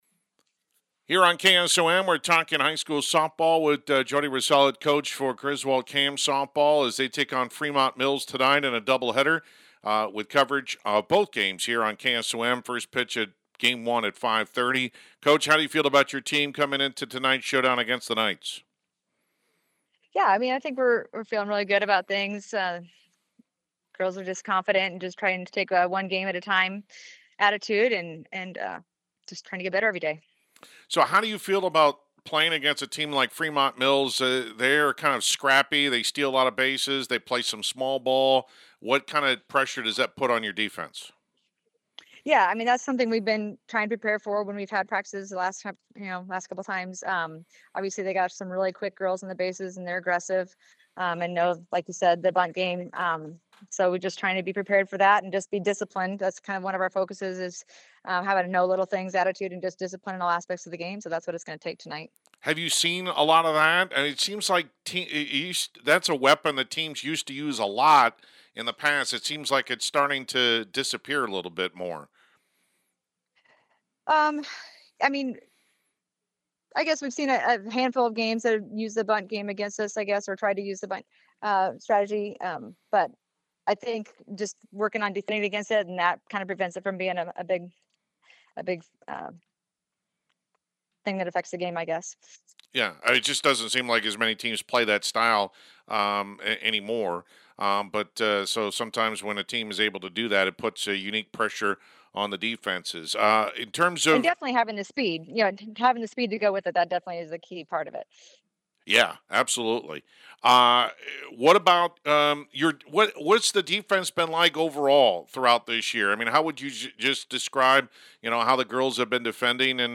griswold-cam-softball-7-1.mp3